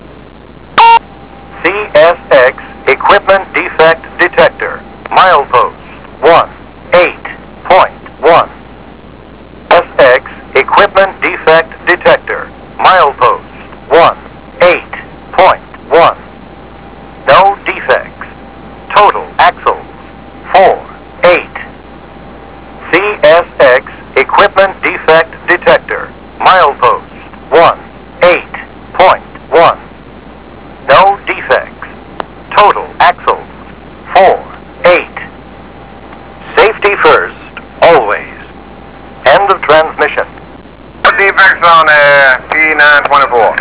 This Equipment Defect Detector at Ridgeville checks for dragging equipment (between and outside rails), and hotboxes (outside rails) monitor temperature in case excess friction is causing a wheel to overheat. After a train passes a report is automatically broadcast via radio signal.
sound recording of detector at mp 18.1 (Daniels) (340K WAV file)